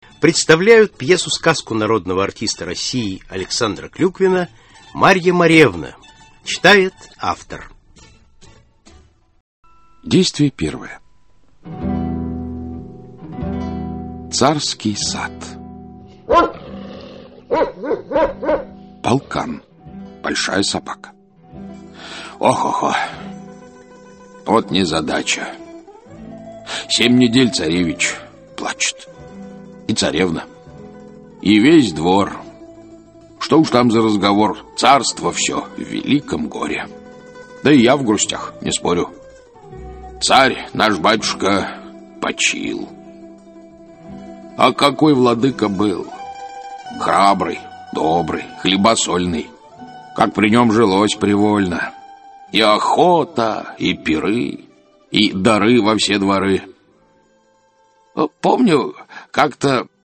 Аудиокнига Марья Моревна | Библиотека аудиокниг
Aудиокнига Марья Моревна Автор Александр Клюквин Читает аудиокнигу Александр Клюквин.